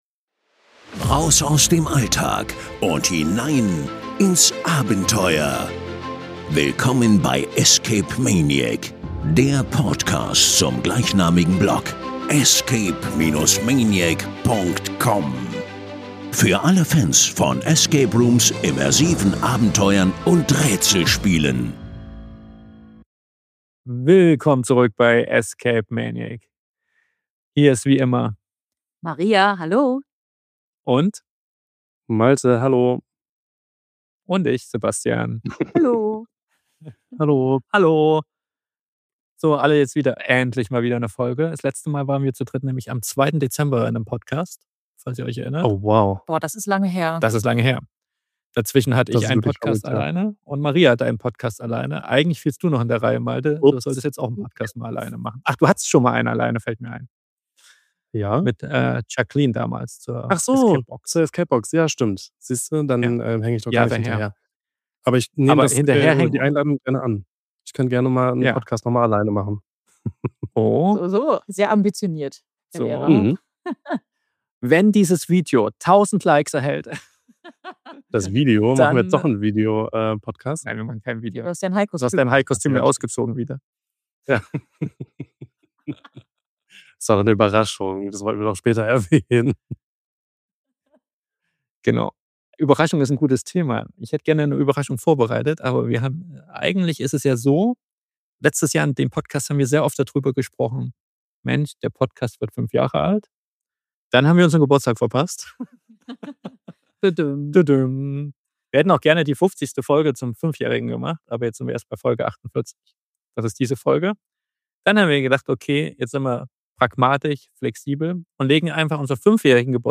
Kein großer Rückblick mit Zahlen, sondern ein persönliches Gespräch über das, was uns wirklich wichtig geblieben ist.